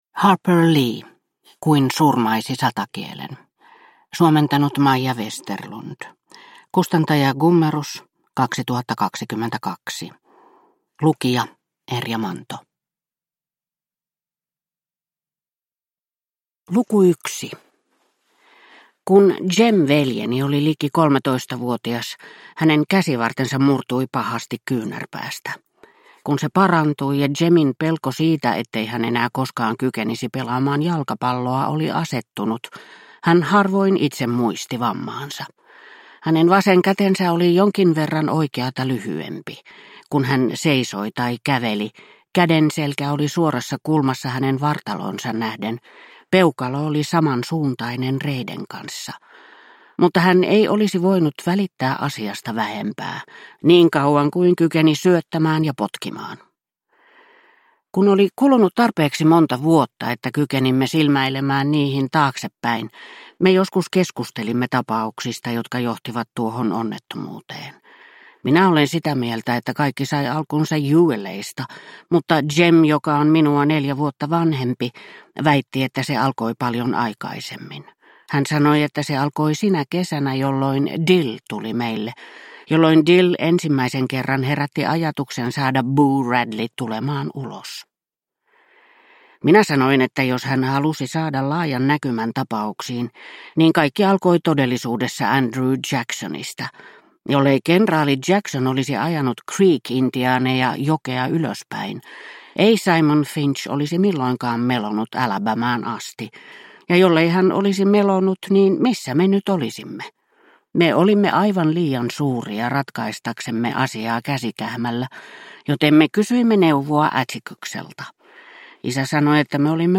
Kuin surmaisi satakielen – Ljudbok – Laddas ner